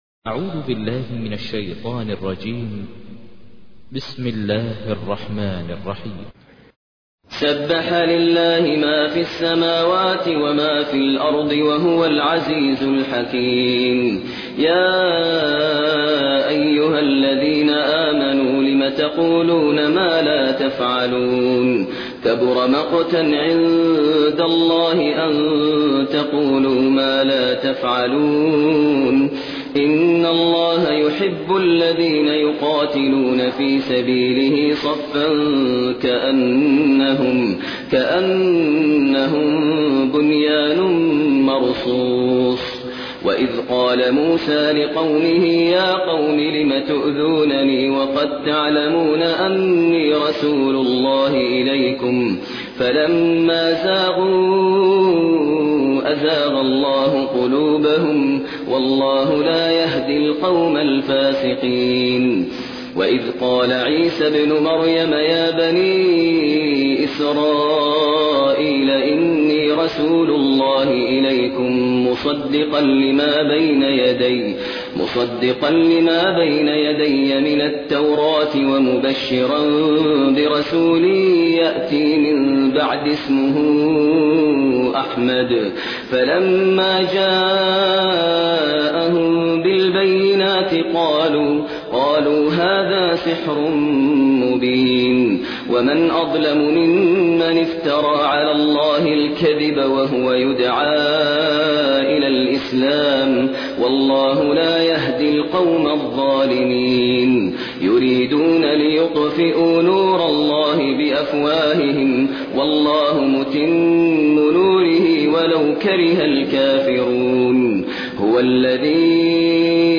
تحميل : 61. سورة الصف / القارئ ماهر المعيقلي / القرآن الكريم / موقع يا حسين